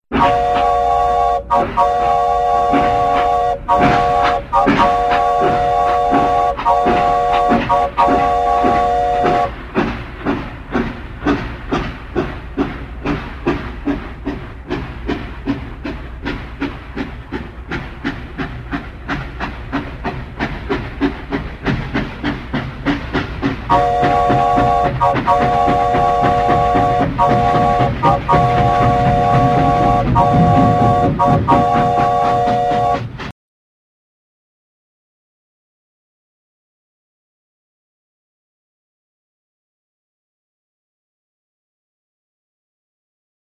Звуки гудков паровоза
Паровоз трогается и гудит